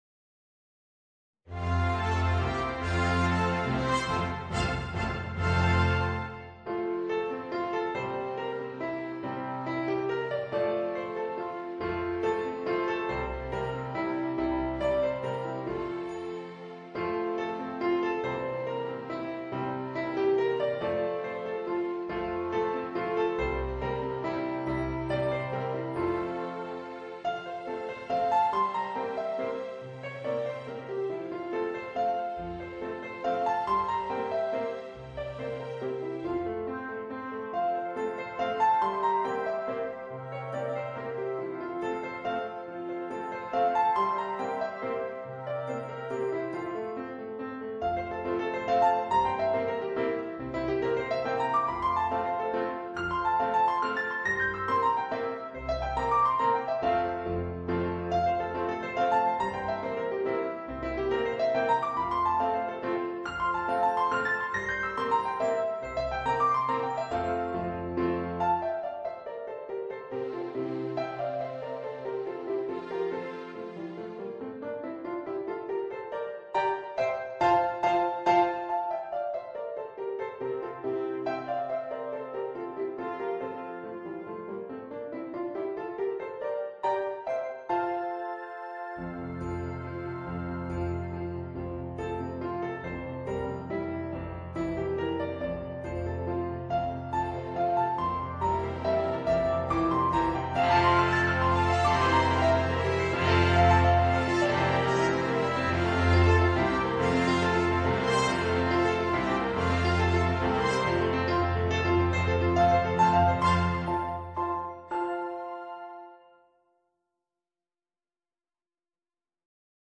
Voicing: Piano and Orchestra